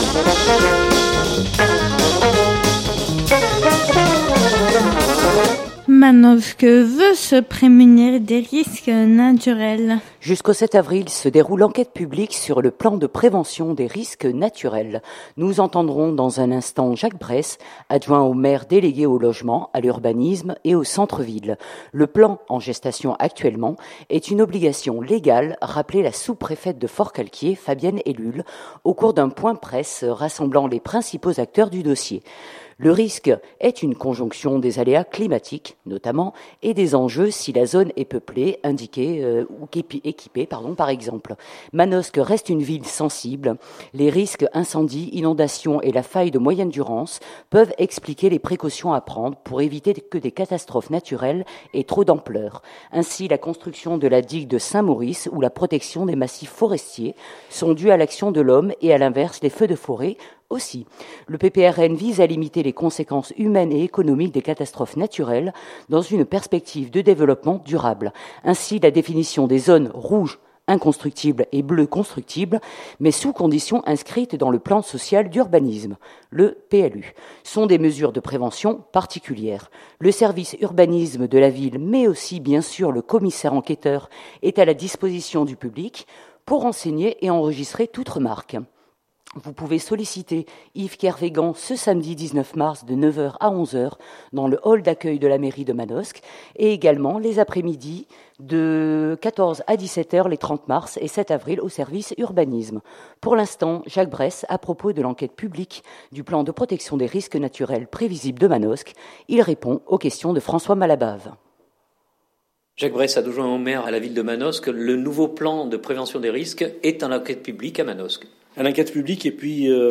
Jusqu’au 7 avril se déroule l’enquête publique sur le Plan de prévention des risques naturels. Nous entendrons dans un instant Jacques Bres, adjoint au maire délégué au logement, à l’urbanisme et au centre-ville.